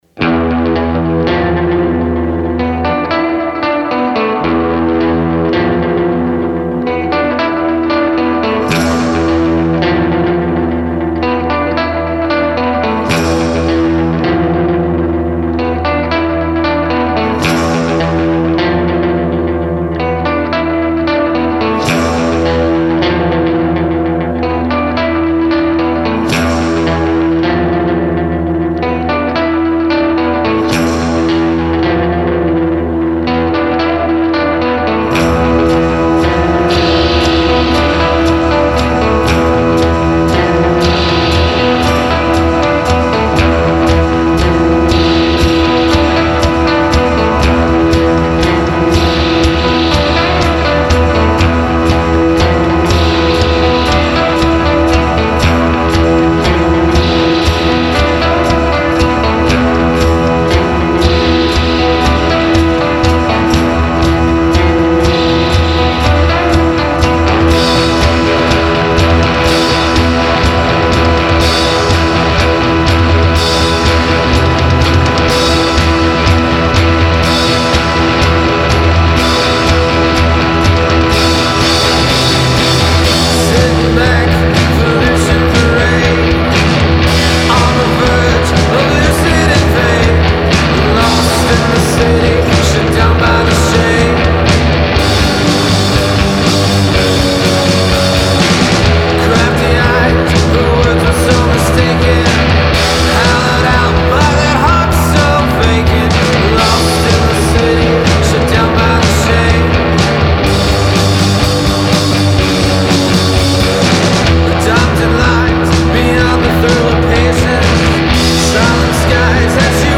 crashing confirmation of the howling, heavy sound